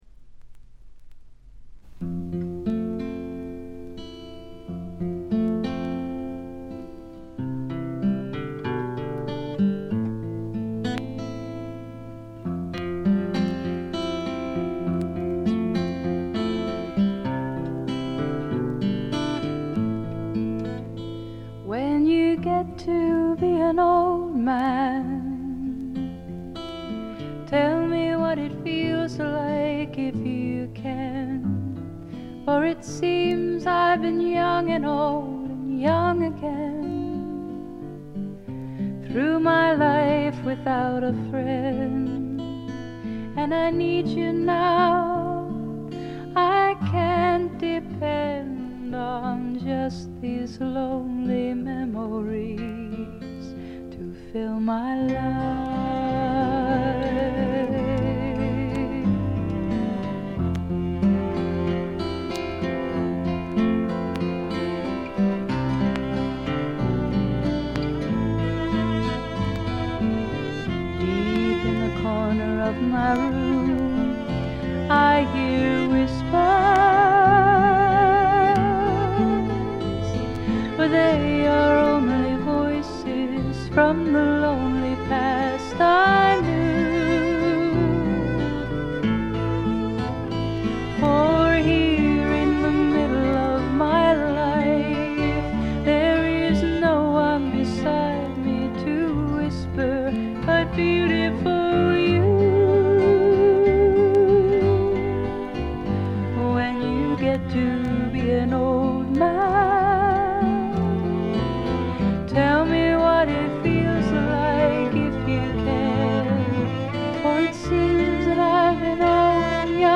ほぼ全曲自作もしくは共作で、ギター弾き語りが基本の極めてシンプルな作りです。
試聴曲は現品からの取り込み音源です。